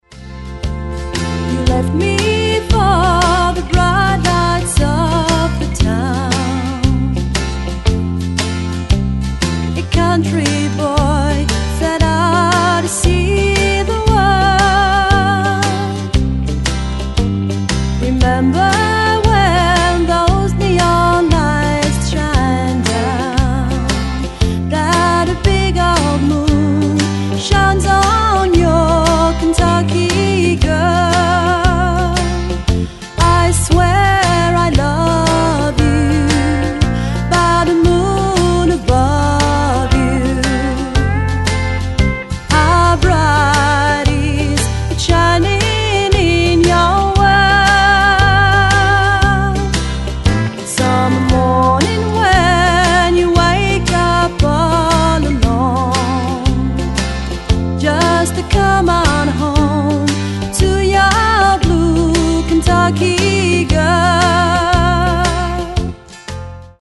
Chant, Harmonies
Pedal Steel Guitare
Guitare Acoustique & Electrique
Basse
Batterie